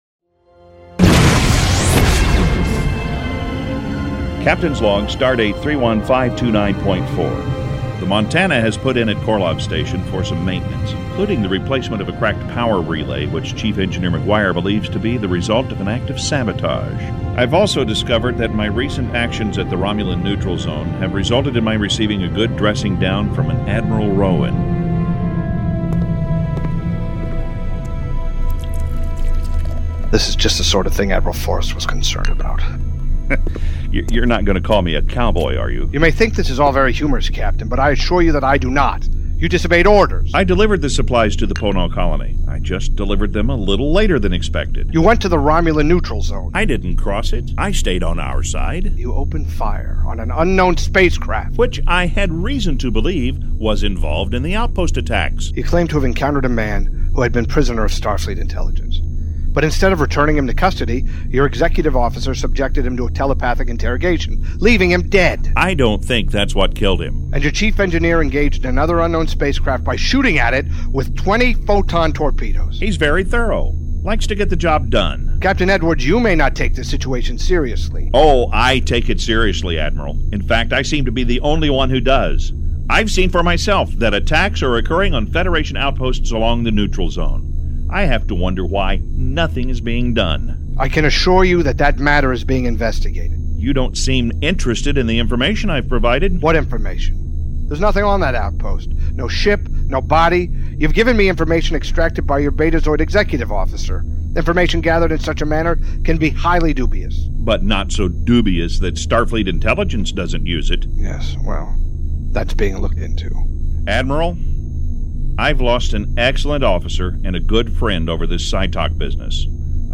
These are the Voyages… for “Star Trek” fans, the voyages go on in the form of an internationally produced, fan audio drama series called “Star Trek: The Continuing Mission”.
Seven-minute Teaser for “Star Trek: The Continuing Mission” Season Finale “We Will Control All That You See and Hear”